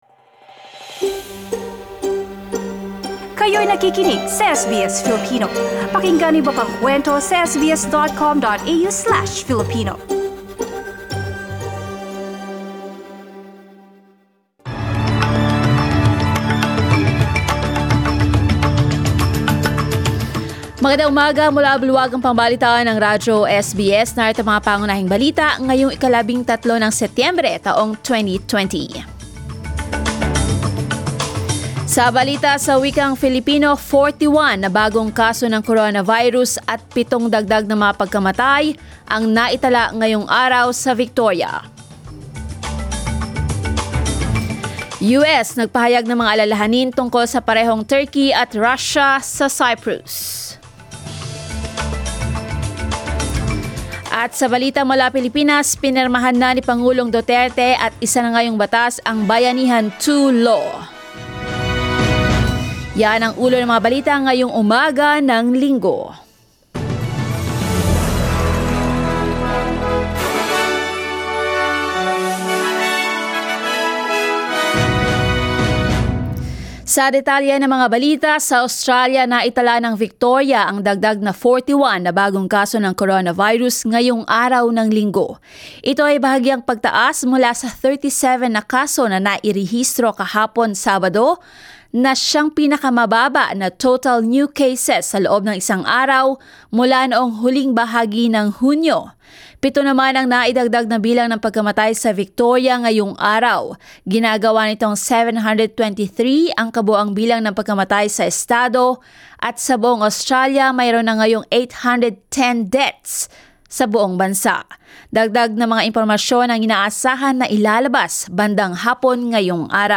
SBS News in Filipino, Sunday 13 September